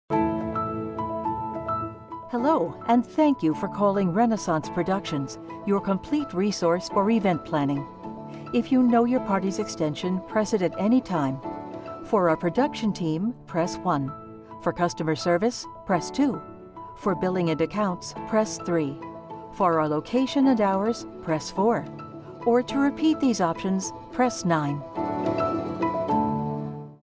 Female
My voice is friendly & warm, engaging & trustworthy, professional, dynamic, bubbly & entertaining.
Phone Greetings / On Hold
Corporate Phone/Ivr Greeting
Words that describe my voice are Engaging, Professional, Dynamic.
All our voice actors have professional broadcast quality recording studios.